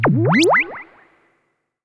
Index of /musicradar/sci-fi-samples/Theremin
Theremin_FX_14.wav